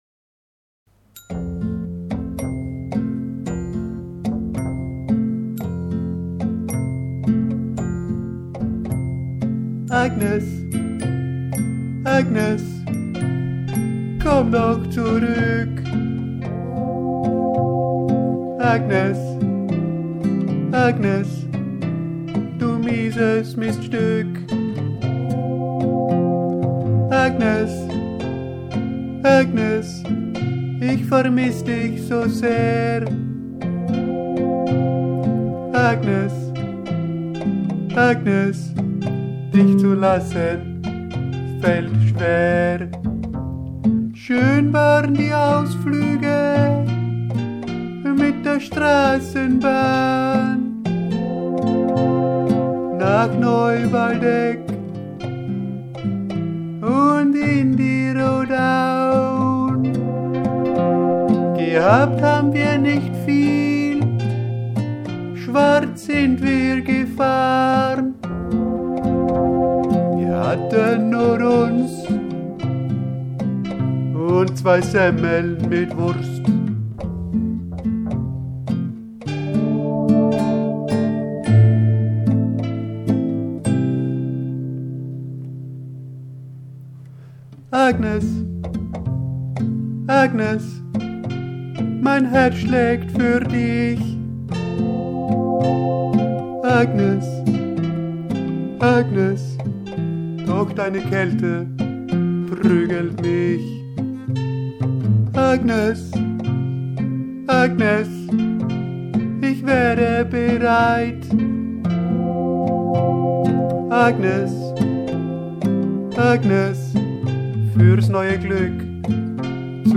Literaturlieder von brüchiger Schönheit, verwegene Tongänge